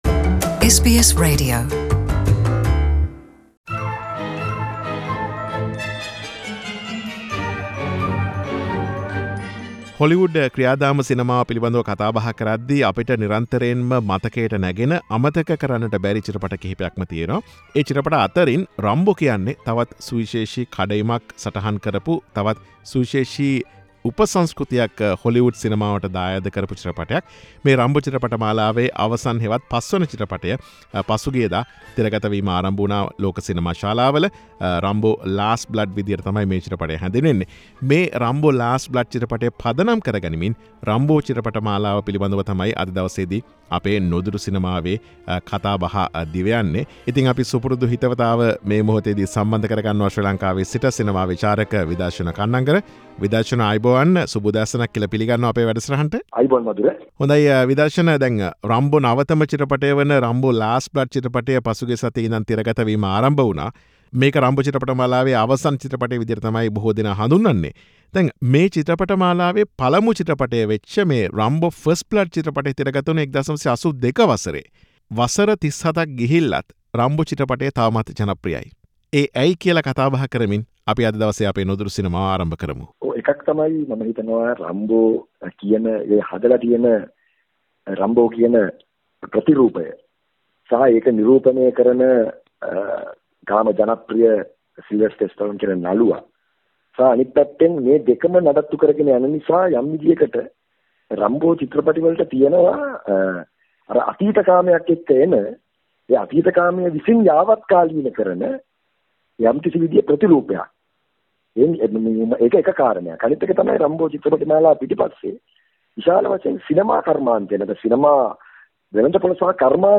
මෙවර SBS සිංහල මාසික සිනමා විශේෂාංගය වන "නුදුටු සිනමාවෙන්" පසුගිය සැප්තැම්බර් 20 වන දින සිට තිරගත වීම ආරම්භ වූ නවතම Rambo: Last Blood චිත්‍රපටය ඇතුළු Rambo චිත්‍රපට මාලාව පිළිබඳ විමසා බැලීමක්. සිනමා විචාරක